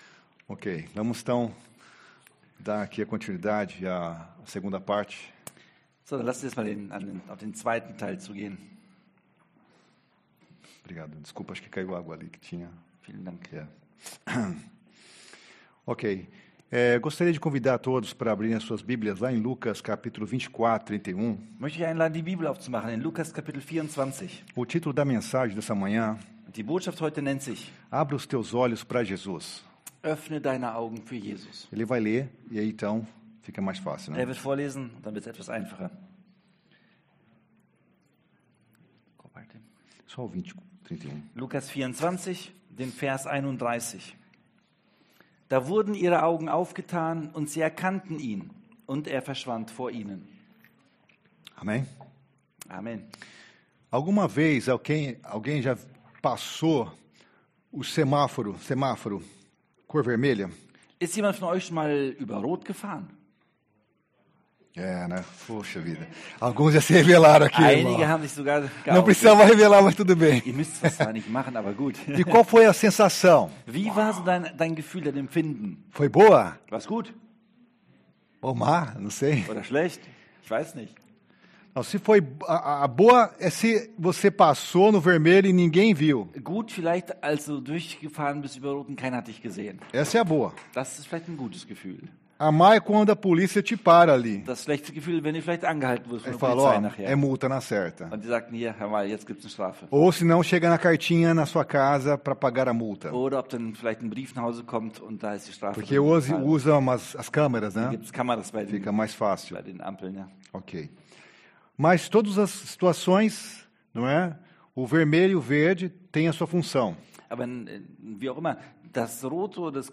Juli 2025 Öffne deine Augen für Jesus Prediger